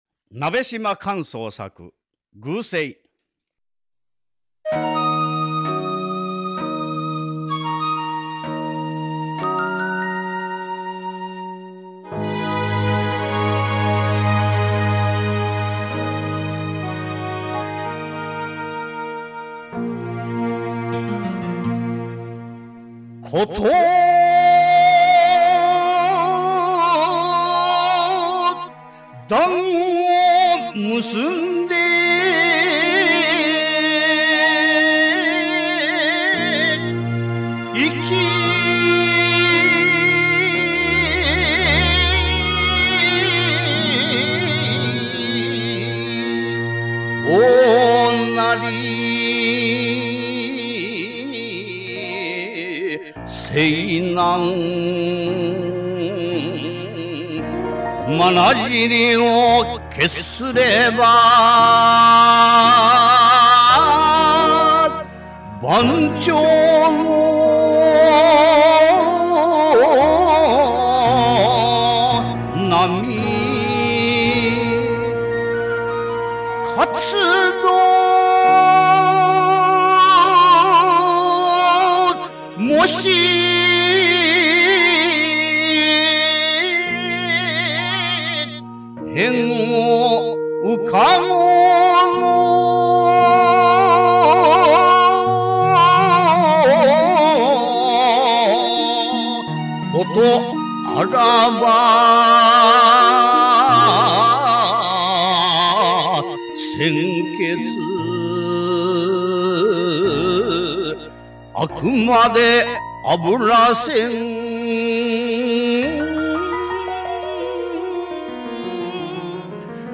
吟者